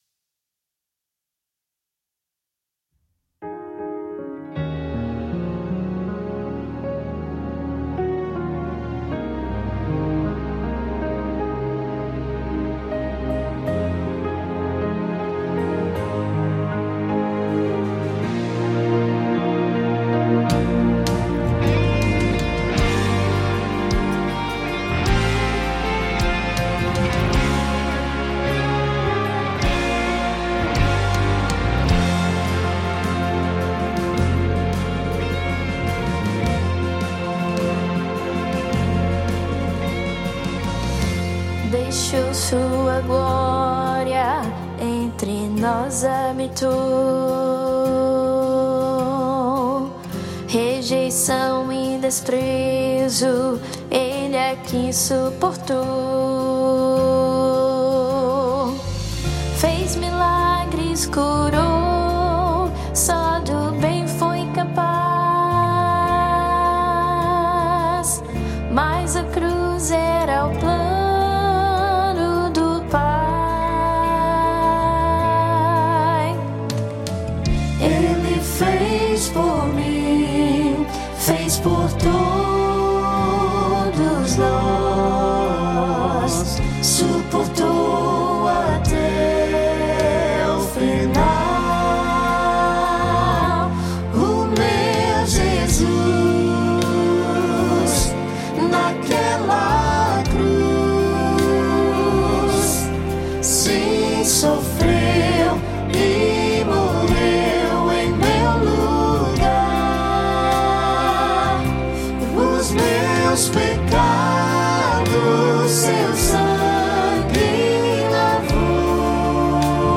O que você receberá ao adquirir a orquestração e os VS
• Piano
• Bateria
• Cordas
• Violão
• Percussão
• Guitarra
• Metais (Trompas)